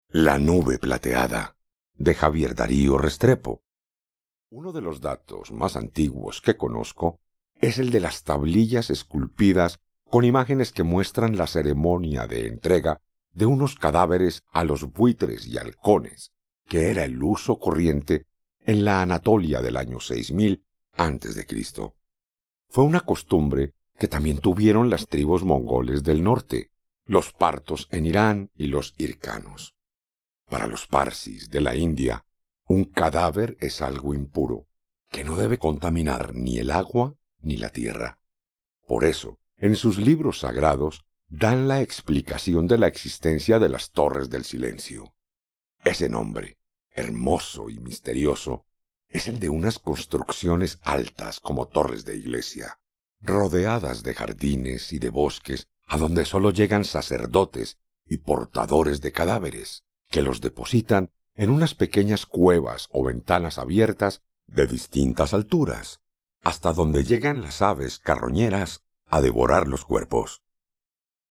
Fragmentos de audiolibros